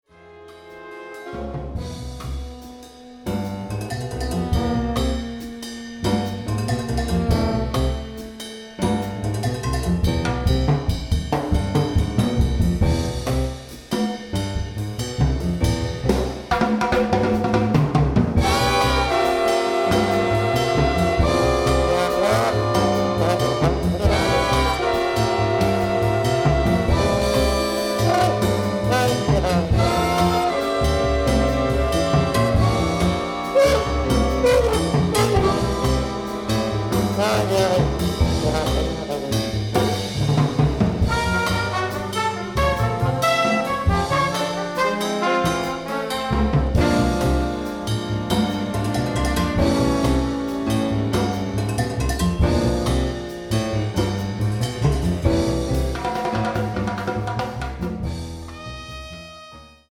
Store/Music/Big Band Charts/ORIGINALS/PORTRAIT
Solos: alto sax/trumpet, drums